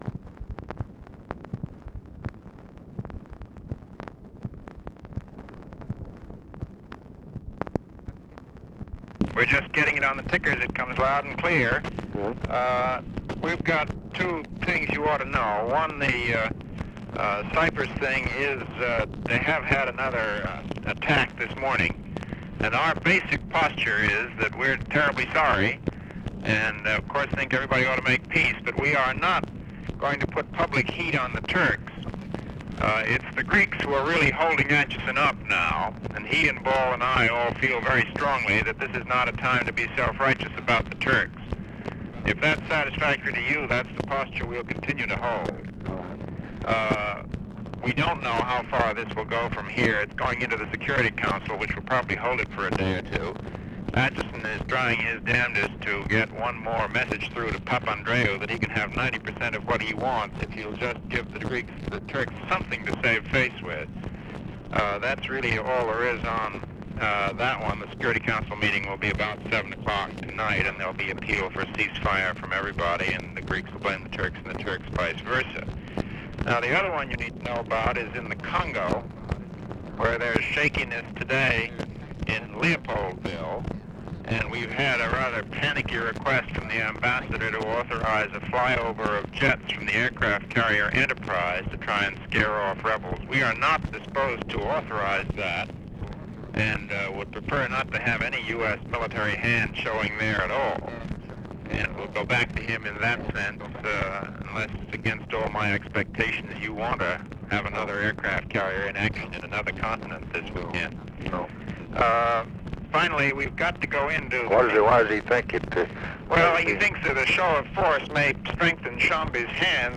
Conversation with MCGEORGE BUNDY, August 8, 1964
Secret White House Tapes